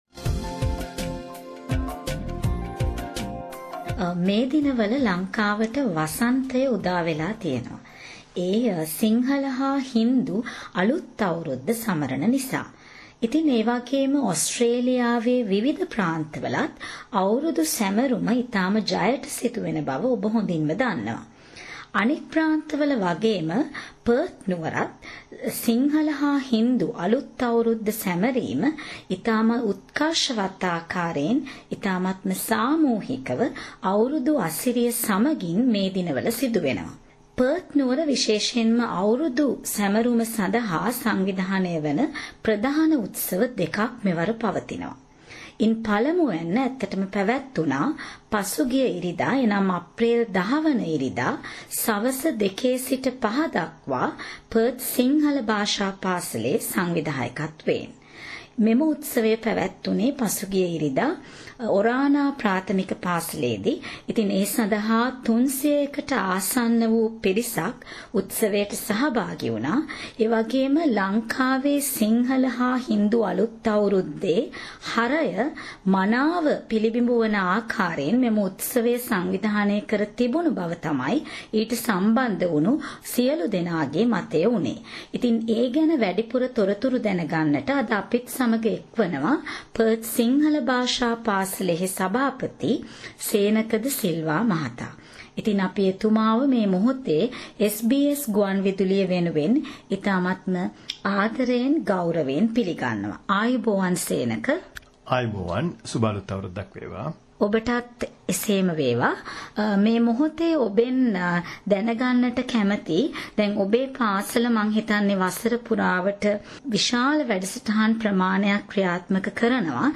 A report on Sinhala New Year celebrations in Western Australia